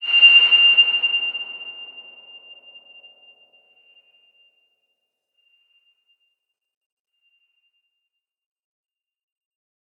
X_BasicBells-F5-mf.wav